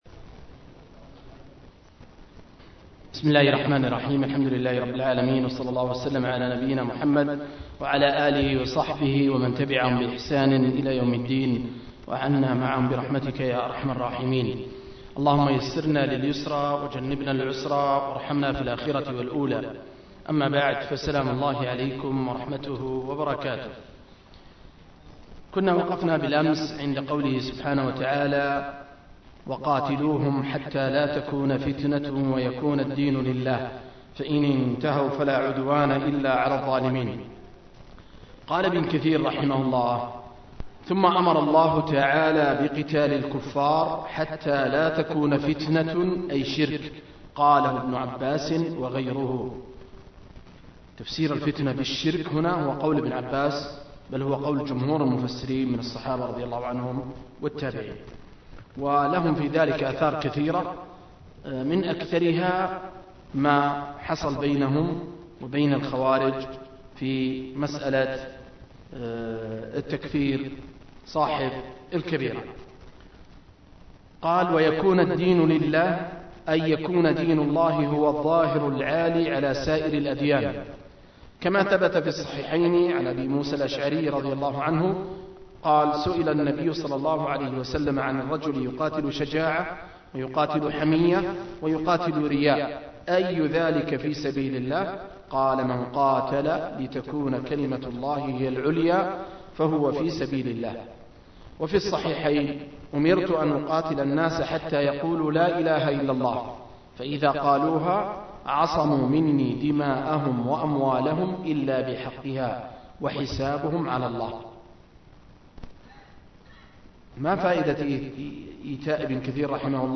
039- عمدة التفسير عن الحافظ ابن كثير – قراءة وتعليق – تفسير سورة البقرة (الآيات 196-190)